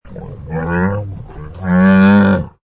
Camel 5 Sound Effect Free Download